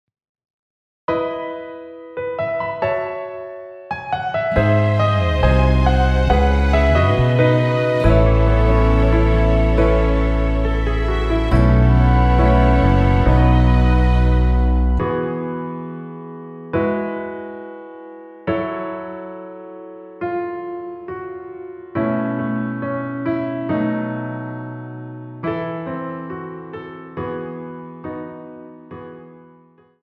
여성분이 부르실 수 있는키로 제작하였습니다.(미리듣기 확인)
원키에서(+4)올린 MR입니다.
앞부분30초, 뒷부분30초씩 편집해서 올려 드리고 있습니다.